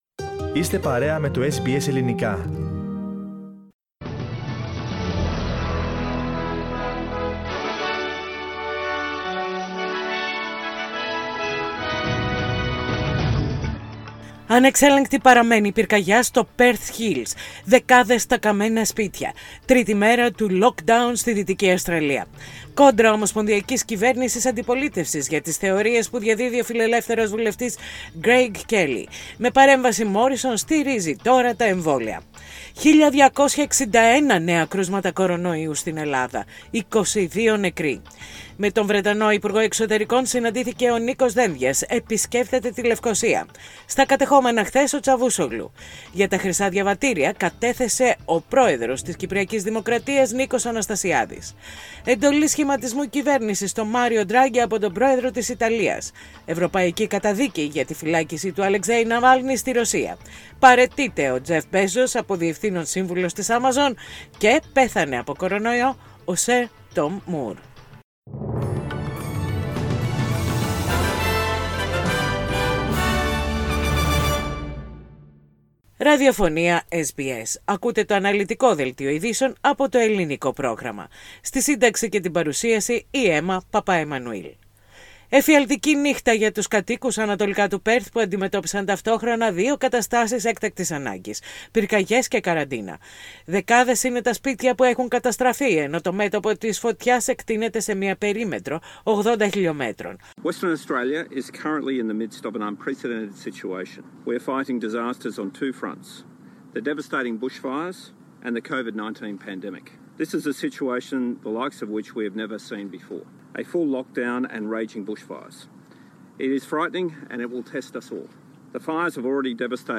Δελτίο Ειδήσεων - Τετάρτη 3.2.21